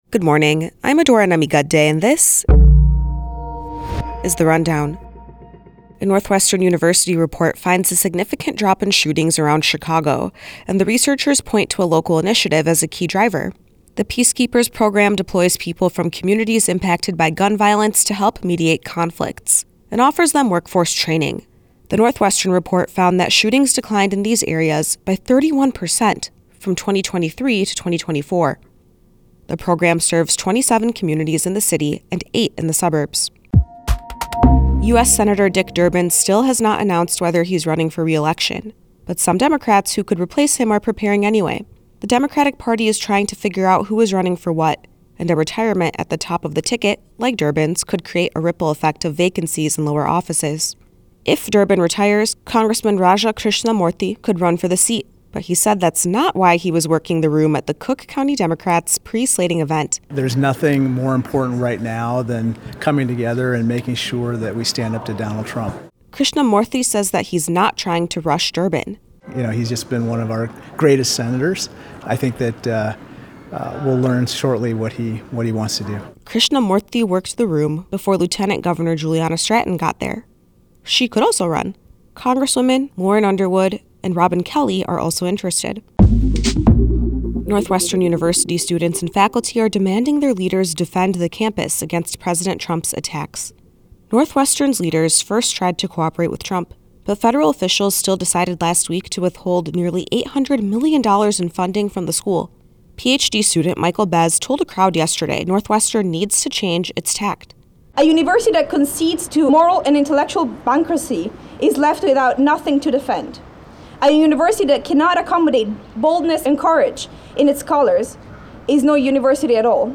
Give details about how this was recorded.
The Rundown podcast visited the DNC and spoke with a whole bunch of attendees who see the DNC in Chicago as a unique opportunity – for all kinds of different reasons.